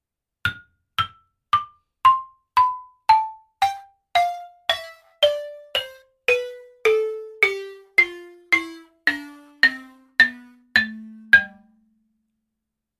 7音階、21鍵盤。C(ハ長調)のソからファまで3オクターブ弱。
固めのマレットで